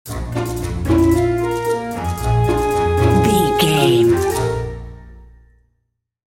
Uplifting
Lydian
B♭
flute
oboe
strings
orchestra
cello
double bass
percussion
silly
circus
goofy
comical
cheerful
perky
Light hearted
quirky